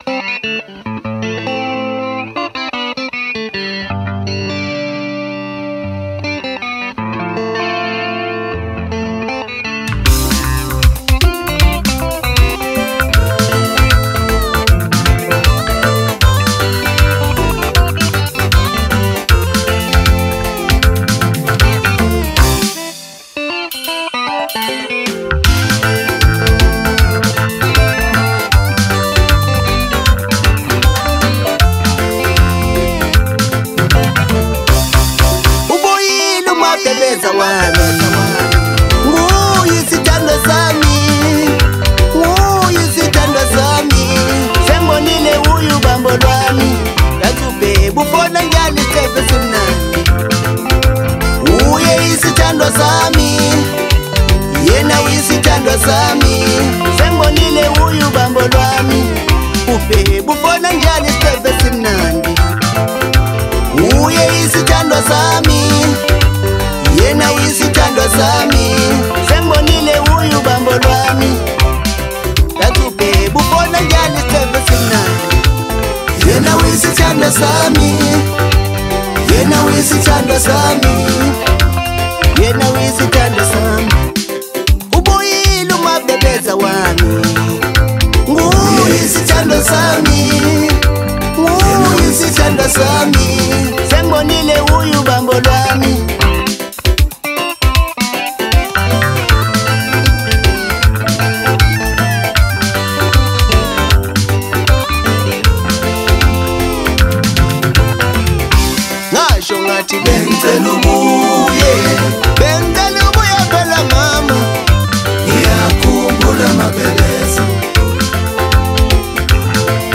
Home » Maskandi Music » Maskandi